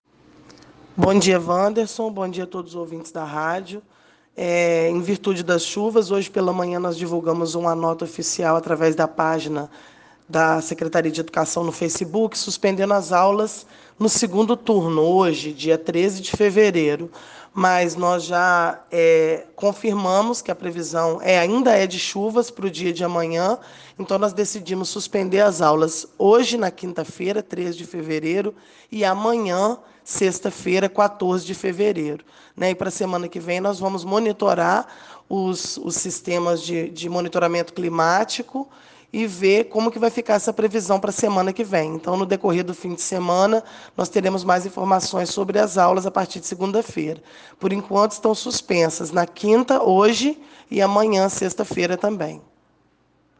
Por conta das fortes chuvas que castigaram Natividade nesta madrugada, as aulas estão suspensas nesta quinta e sexta-feira, dias 13 e 14, em toda a rede municipal de ensino. A informação foi confirmada pela secretária Paula Ferreira em declaração à Rádio Natividade.